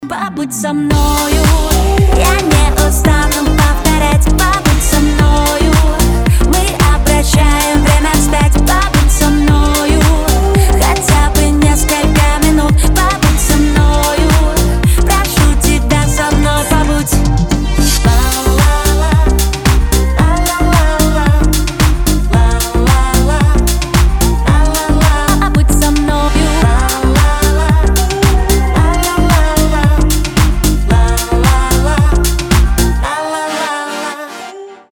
• Качество: 320, Stereo
поп
женский вокал
dance
романтичные